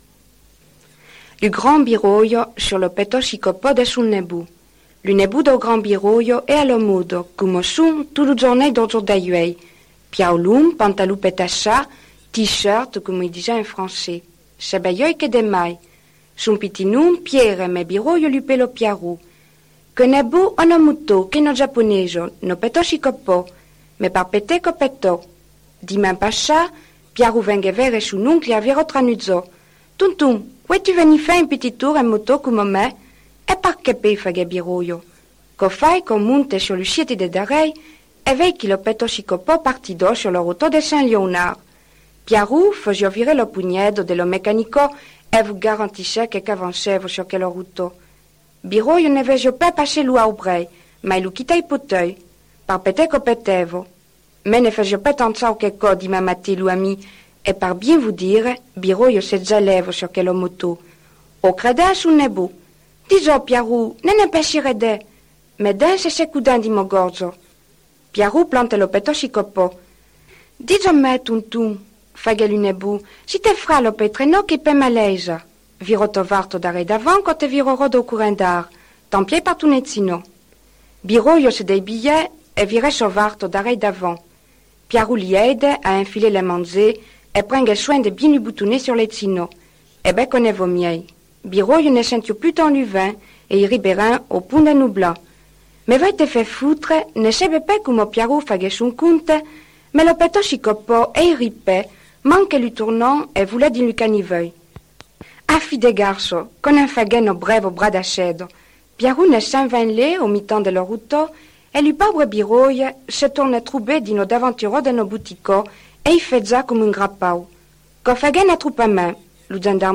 (parler de Nontron)
Dietzesma leiçon :